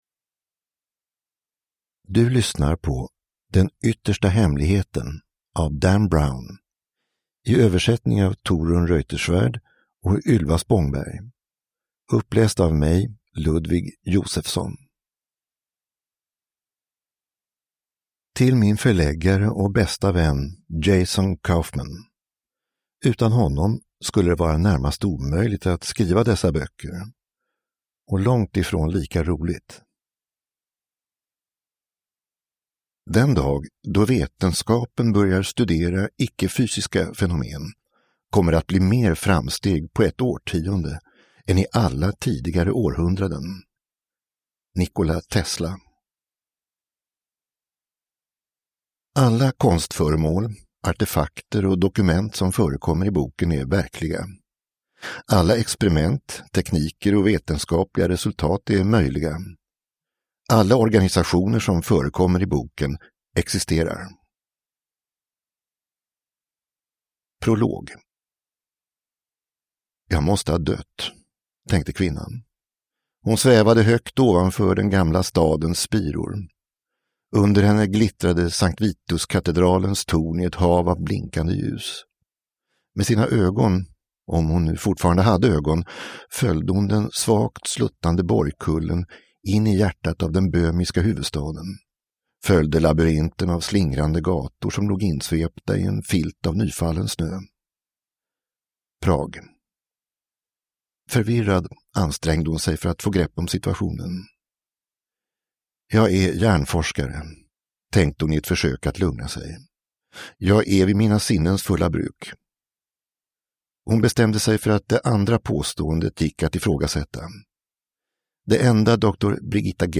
Den yttersta hemligheten – Ljudbok